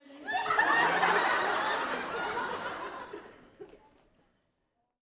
Audience Laughing-00.wav